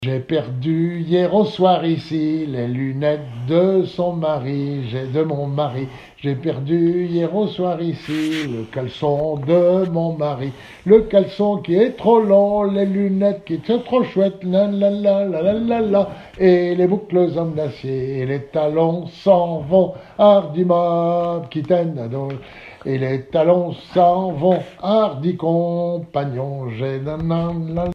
Genre énumérative
Témoignages et chansons
Pièce musicale inédite